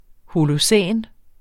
holocæn 1 substantiv Udtale [ holoˈsεˀn ] Oprindelse af græsk holos 'hel' og -cæn afledt af græsk kainos 'ny', dvs.